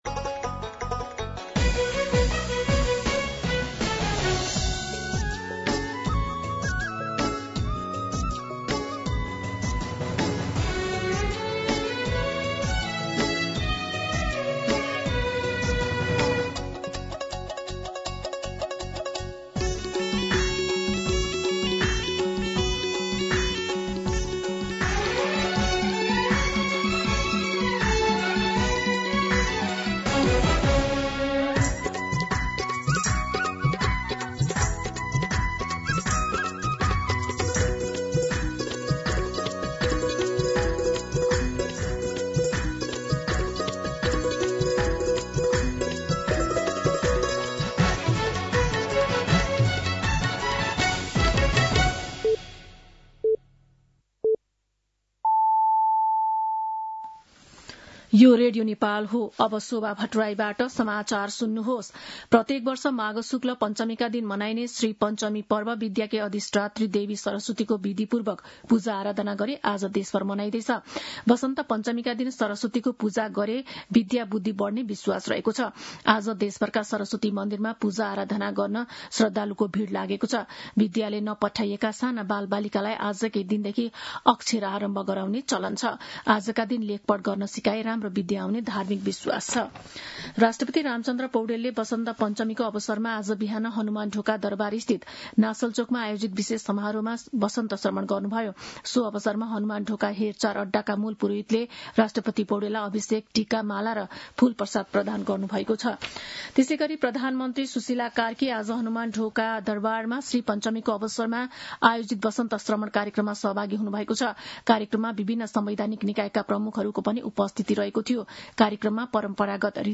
दिउँसो ४ बजेको नेपाली समाचार : ९ माघ , २०८२